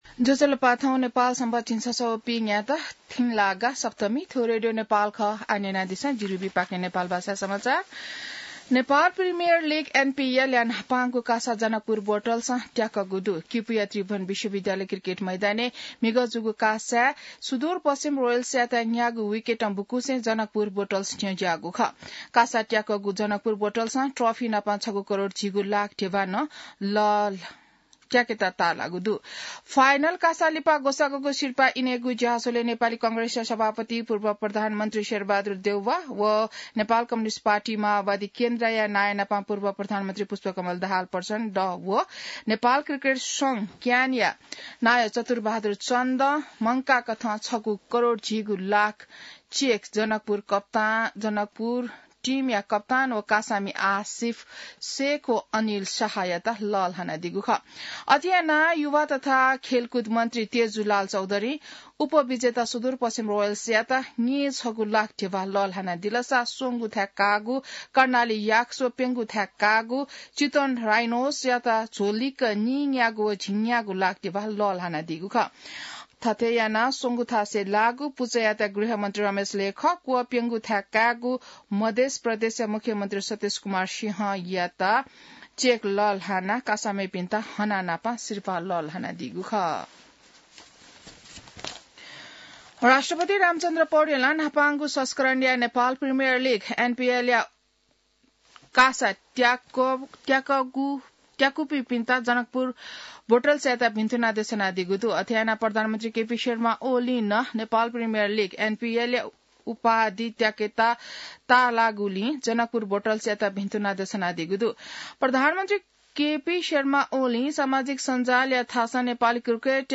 नेपाल भाषामा समाचार : ८ पुष , २०८१